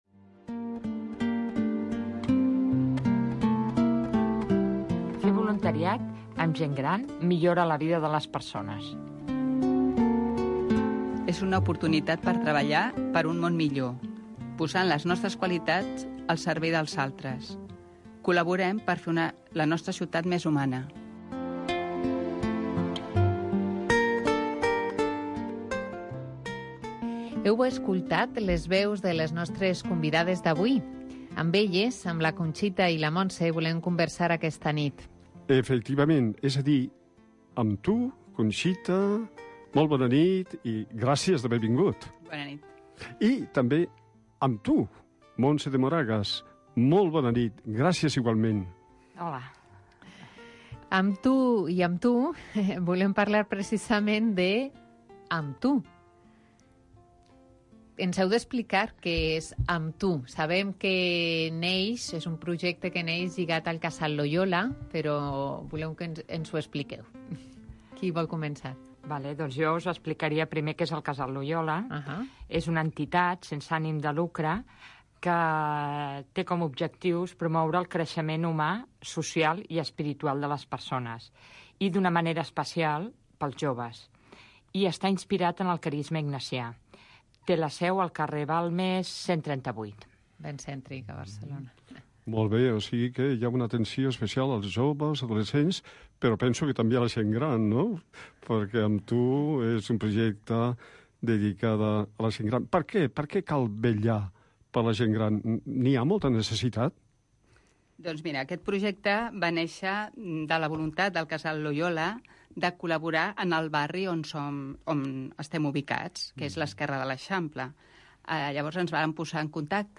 Aquí us deixem l'entrevista perquè en gaudiu i sapigueu més d'aquest preciós projecte (feu clic sobre la següent imatge):